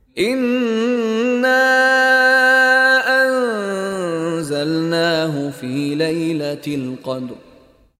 Contoh Bacaan dari Sheikh Mishary Rashid Al-Afasy
DIPANJANGKAN sebutan huruf Mad dengan kadar 4 atau 5 harakat